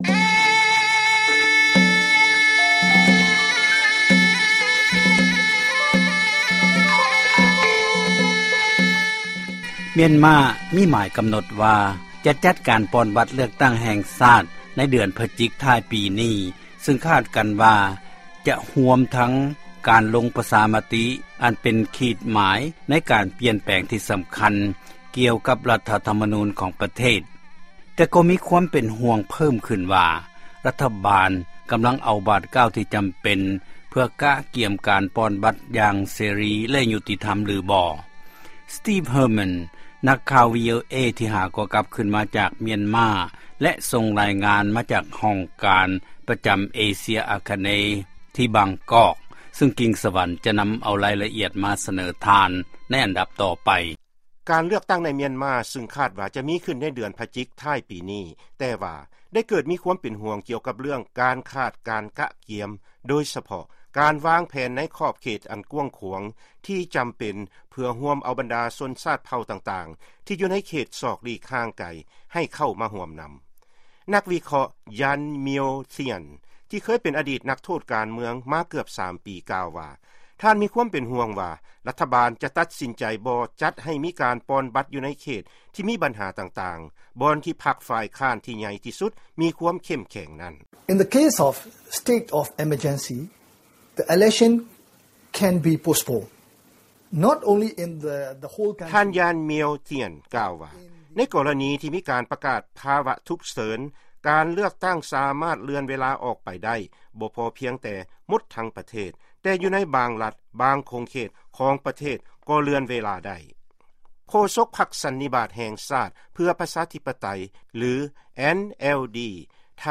ຟັງລາຍງານ ກ່ຽວກັບການປ່ອນບັດເລືອກຕັ້ງ ປະທານາທິບໍດີ ຈະມີຂື້ນ ທີ່ປະເທດ ມຽນມາ